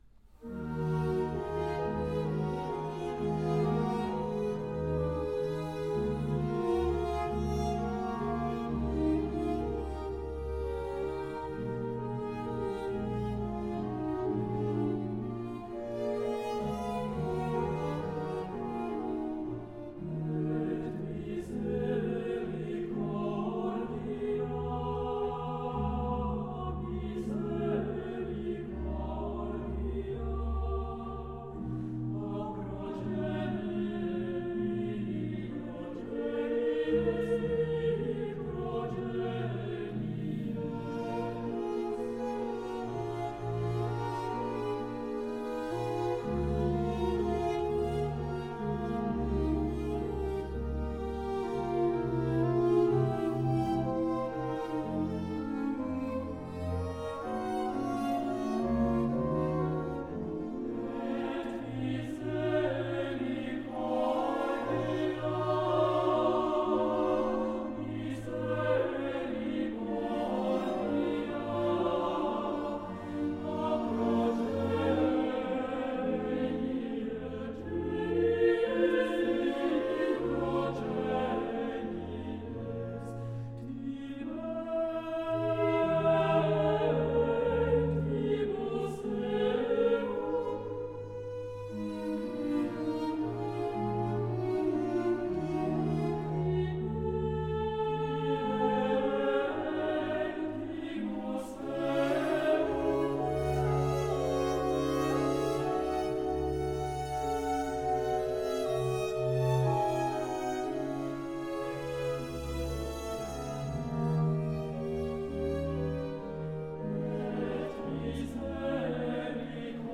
From Magnificat in D major, BWV 243